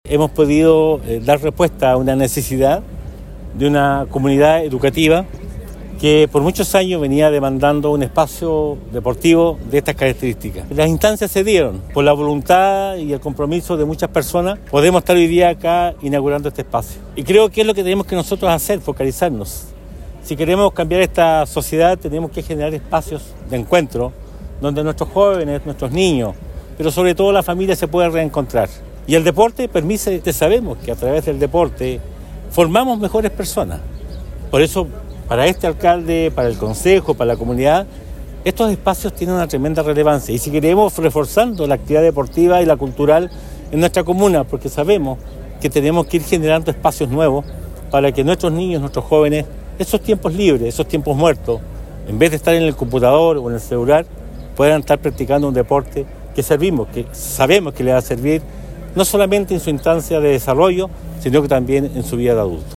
La autoridad comunal, en su discurso, destacó que son este tipo de oportunidades las que deben priorizar los municipios, pues gracias a este convenio es justamente la comunidad joven la favorecida, al contar con un nuevo recinto donde practicar el deporte popular.